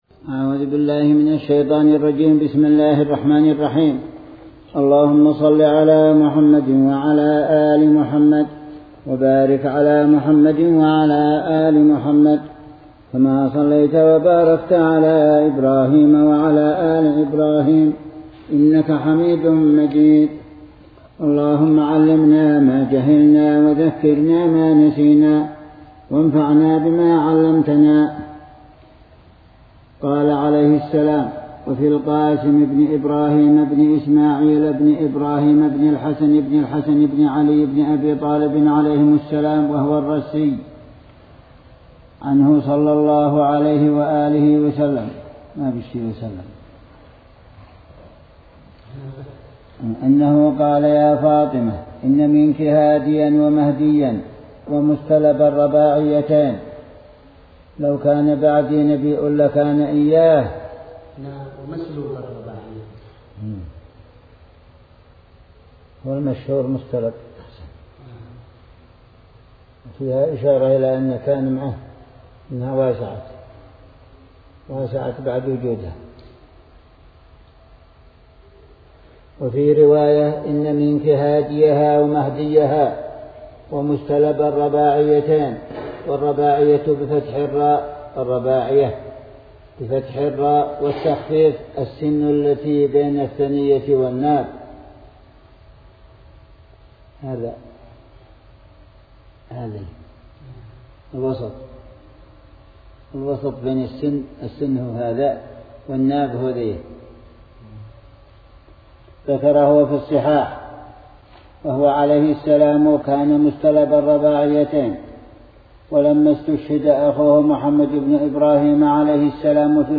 الجمعية العلمية الزيدية - الدروس - 1-2-ش أساس2-خاتمة -ص367